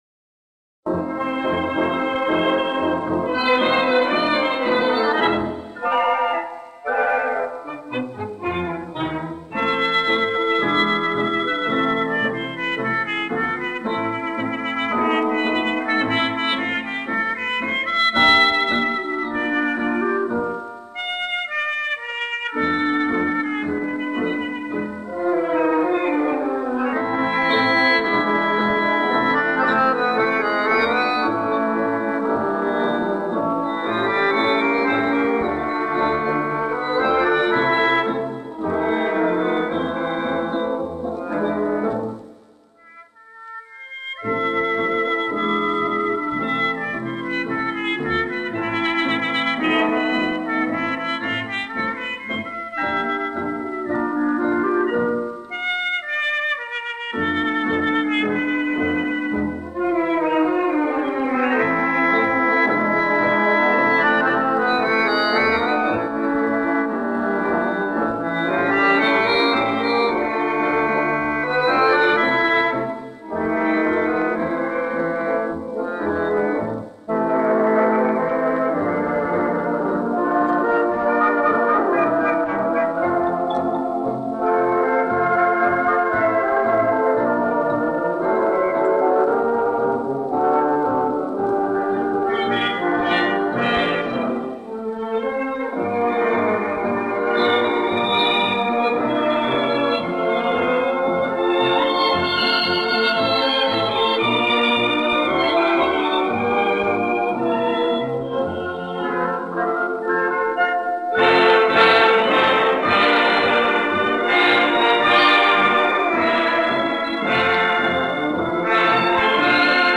лирическое танго